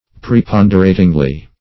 Search Result for " preponderatingly" : The Collaborative International Dictionary of English v.0.48: Preponderatingly \Pre*pon"der*a`ting*ly\, adv. In a preponderating manner; preponderantly.
preponderatingly.mp3